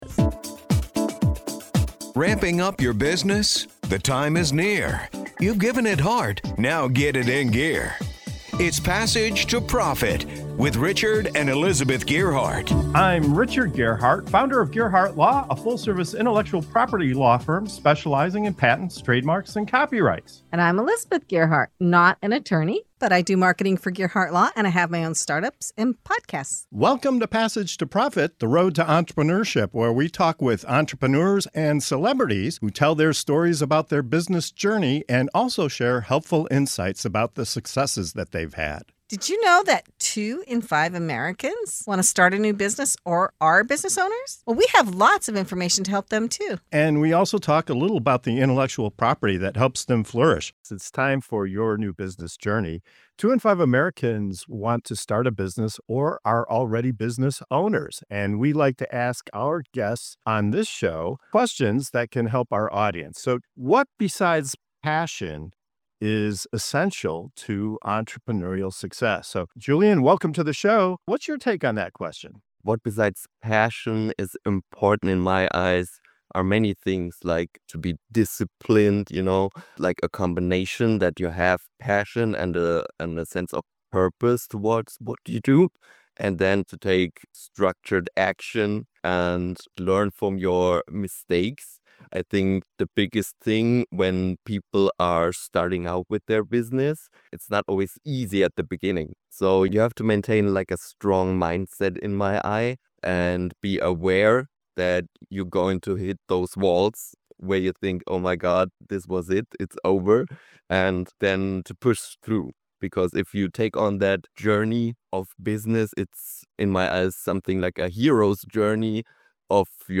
In this segment of “Your New Business Journey” on the Passage to Profit Show, our guests break down the essentials: discipline, self-awareness, grit, and a strong support network. They share powerful insights on embracing failure, building resilience, and understanding the financial foundations of a business.